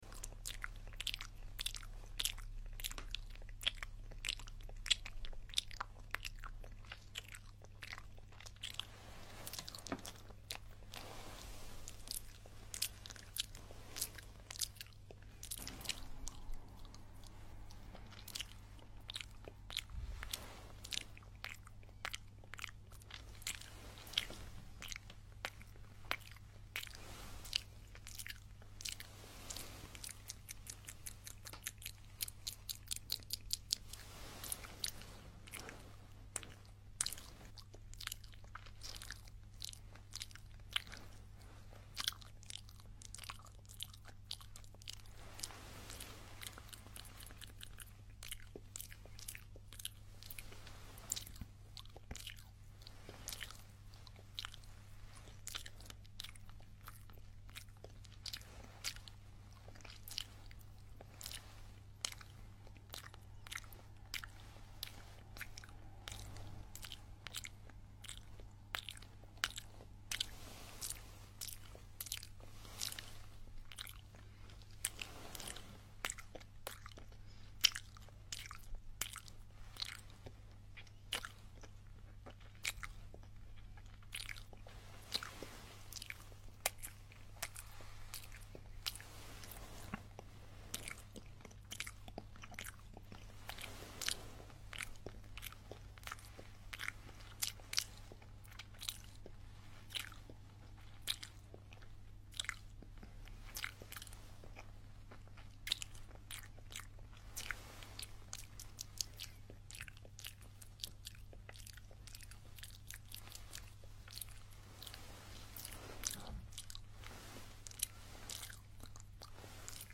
Asmr saliva paint, licking fingers,